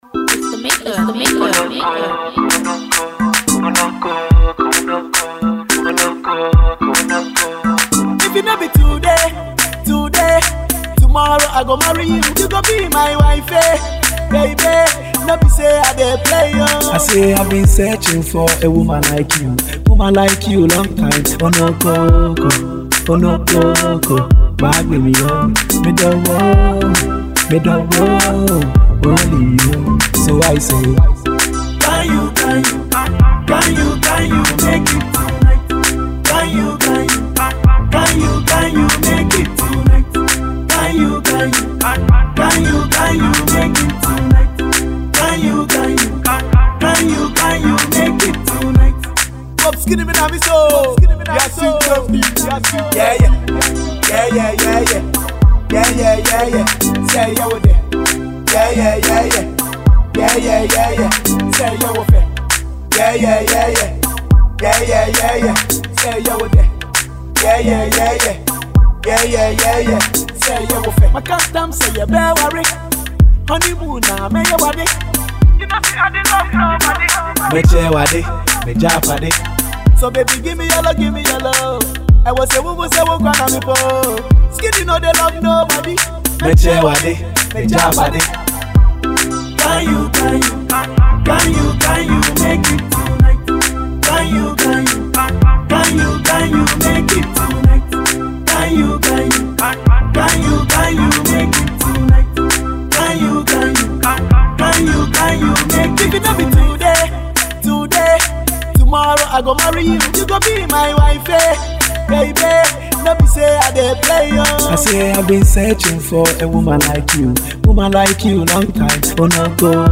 hiplife
danceable love ballad